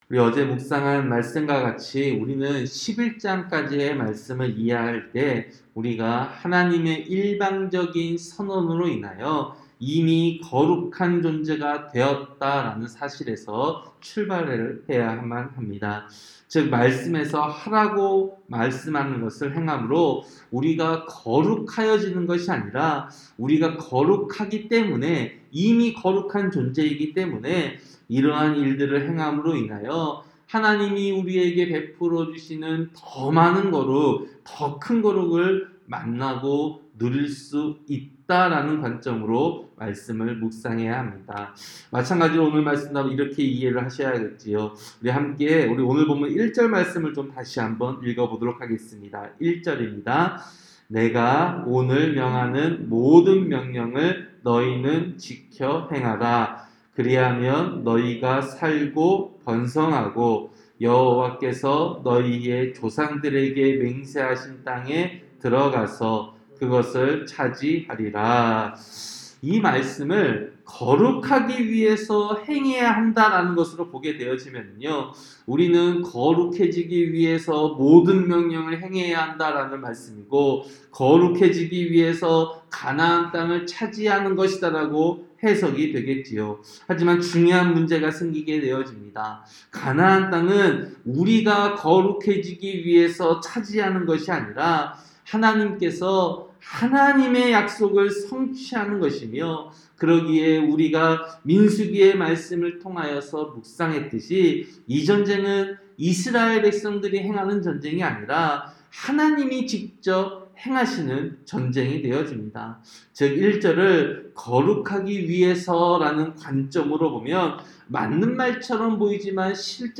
새벽설교-신명기 8장